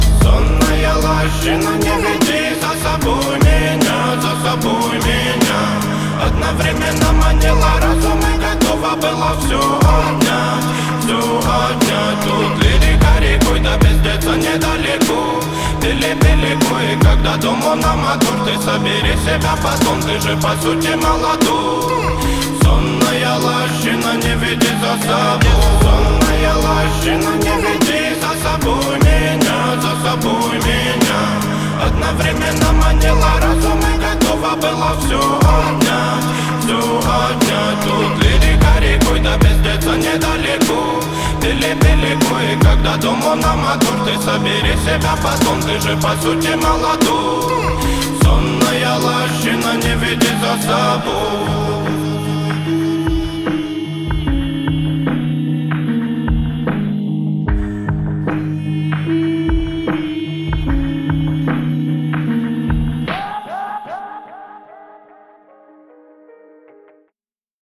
• Качество: 320, Stereo
Хип-хоп
грустные
русский рэп
спокойные
расслабляющие